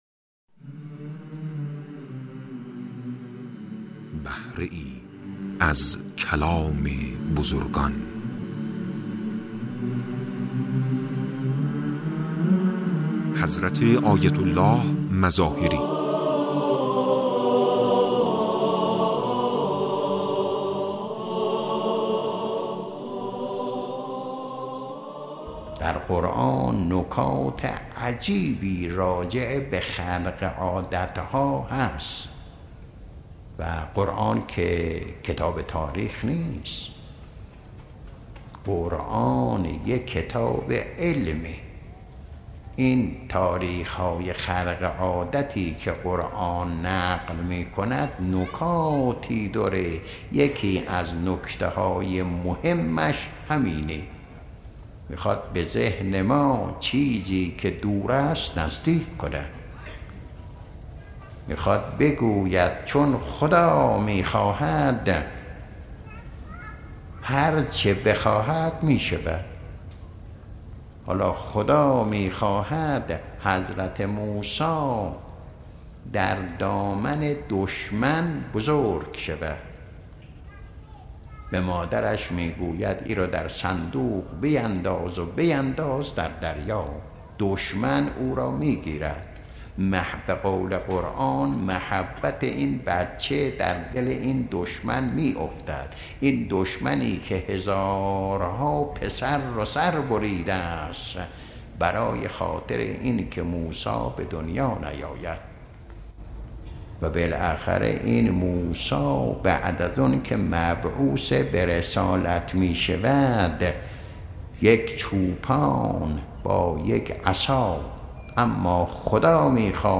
مواعظ گوناگون و مطالب و نکاتی که در قالب صوت هستند و مختصر و مفید می‌باشند و پند و اندرز می‌دهند، در این بخش مطرح می‌شود.